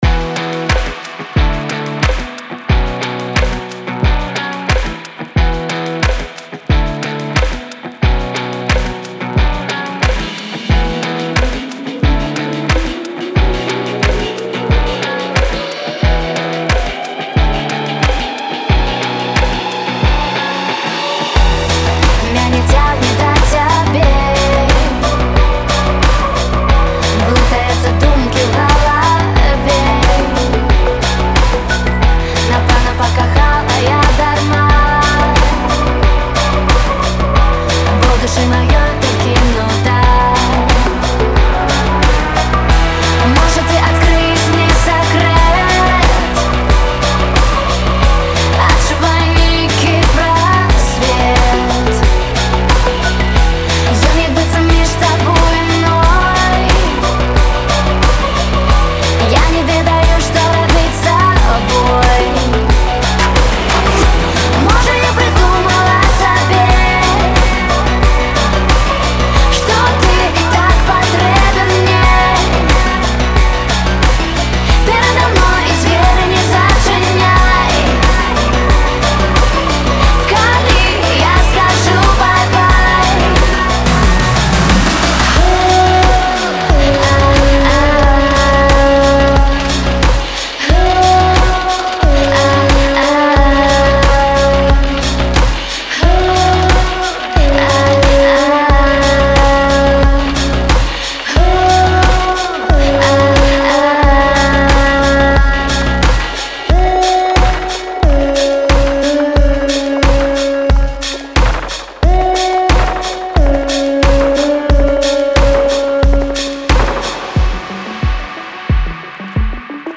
Індзі-поп